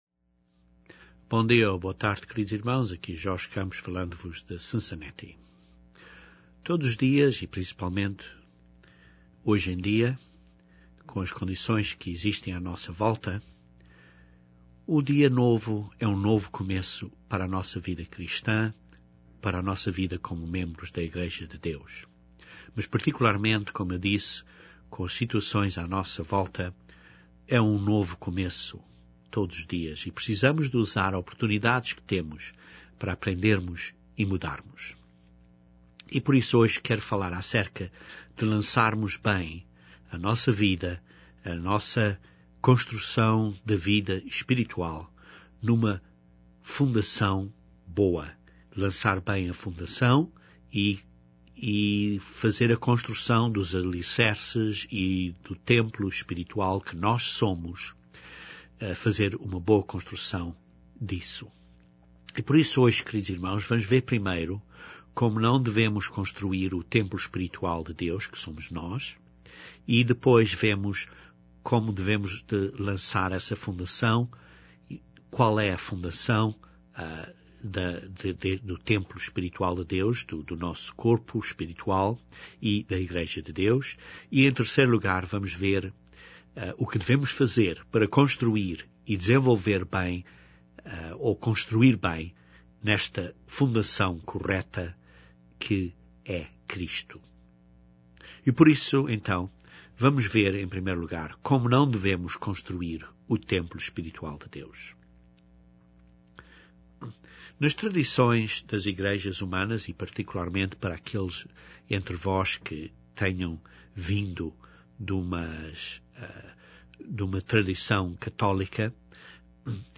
Este sermão depois descreve trêz pontos práticos que podemos fazer para construirmos o templo de Deus.